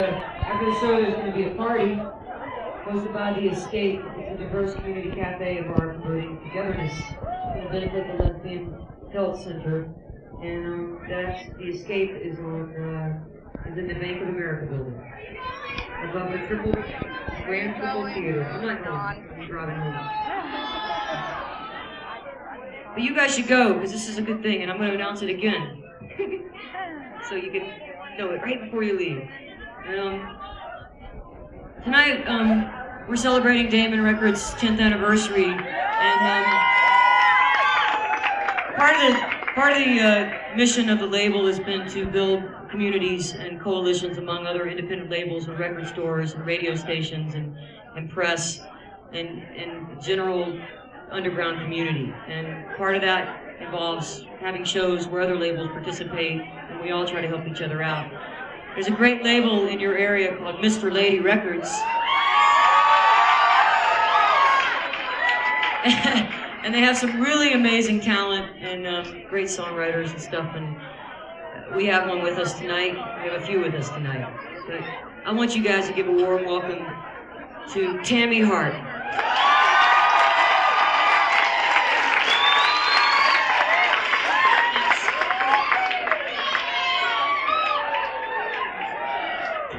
cat's cradle - chapel hill, north carolina